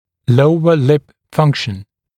[‘ləuə lɪp ‘fʌŋkʃn][‘лоуэ лип ‘фанкшн]функция нижней губы